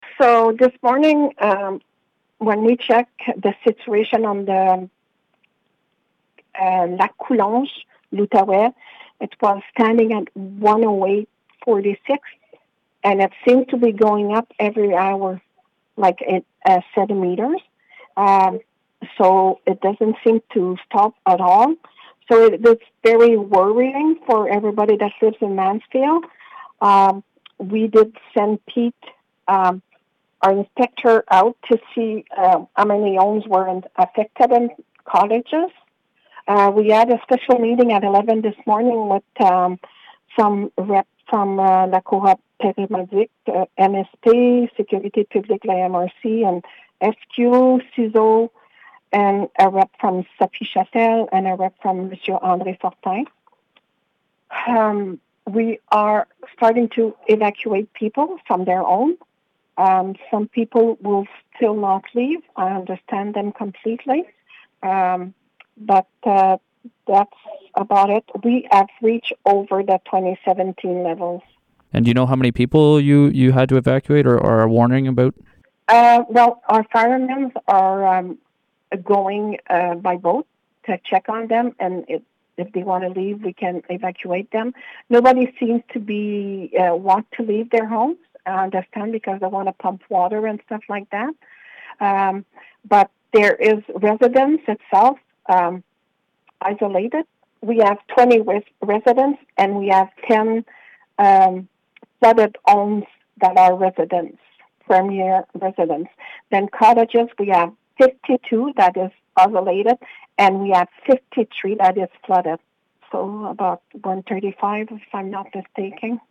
Mayor Sandra Armstrong gave an update just after 3 p.m. on Wednesday's situation.